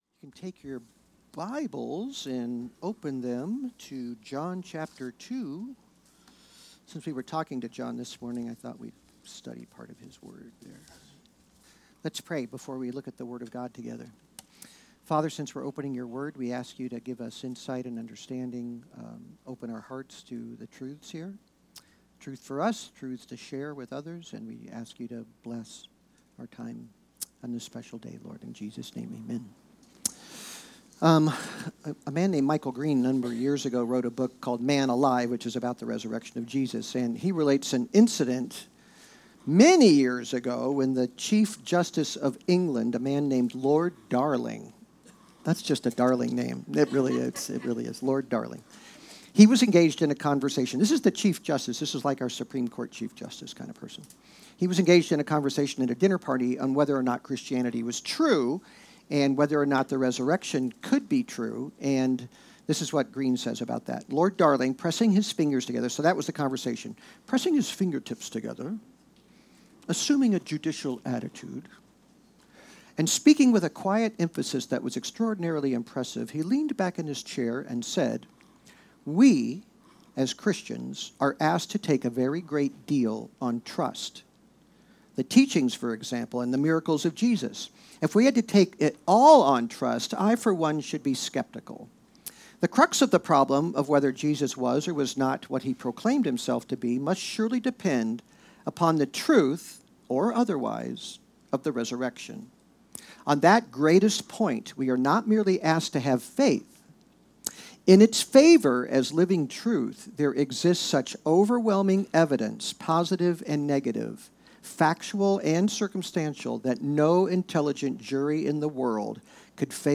Easter Morning message